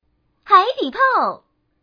Index of /client/common_mahjong_tianjin/mahjongwuqing/update/1161/res/sfx/changsha/woman/